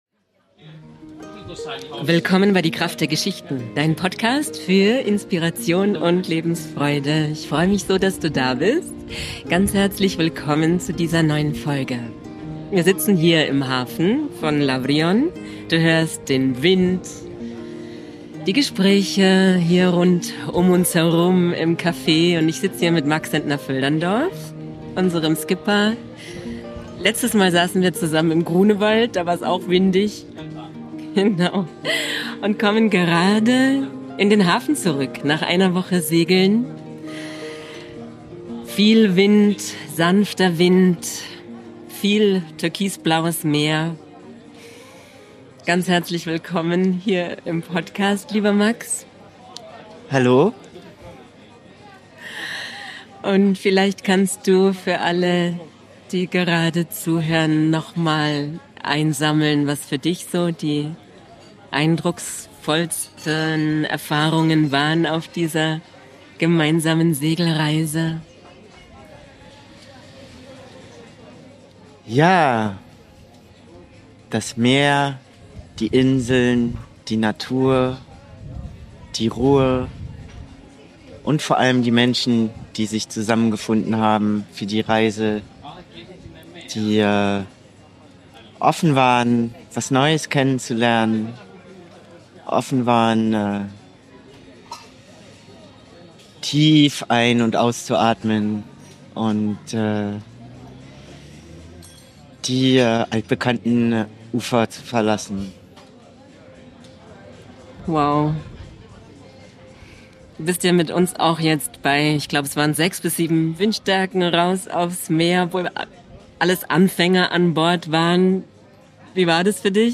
Heute nehmen wir Dich mit in den Hafen von Lavrion.